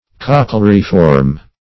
Cochleariform \Coch`le*ar"i*form\